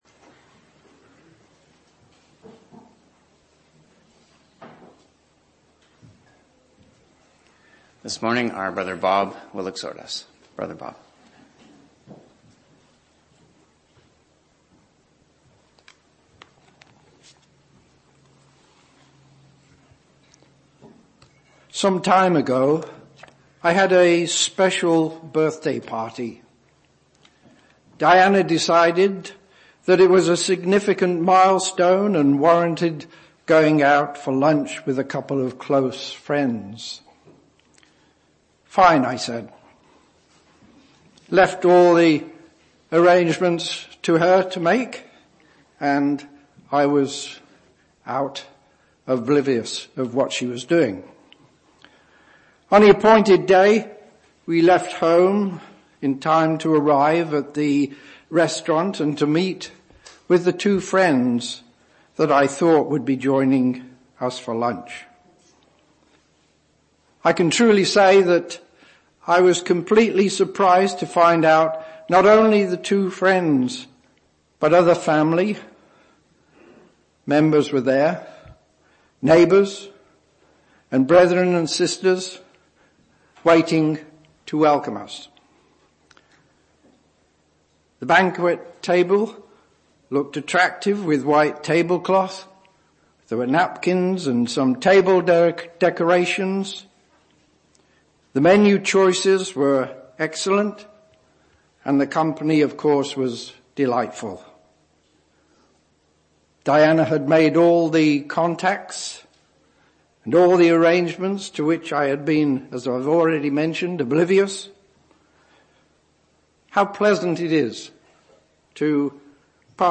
Exhortation 01-12-25